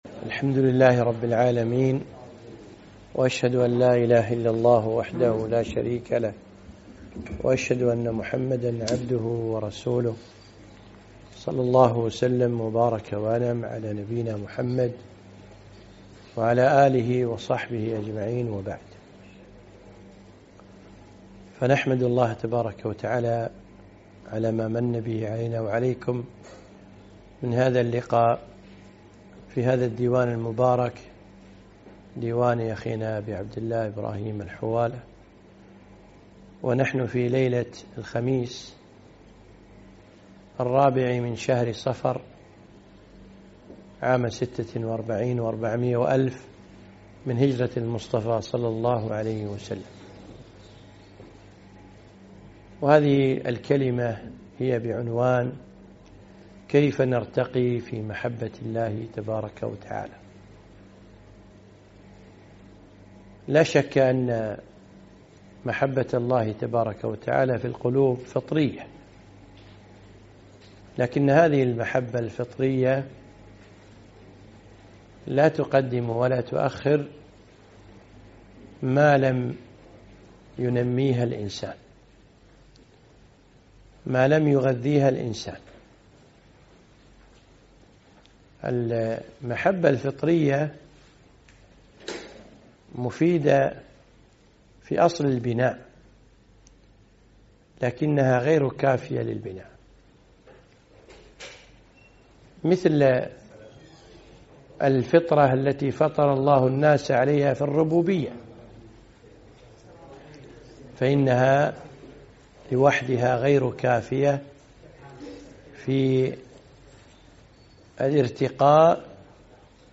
محاضرة - كيف نرتقي في حب الله تعالى؟